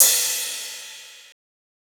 Metro Little Cymb.wav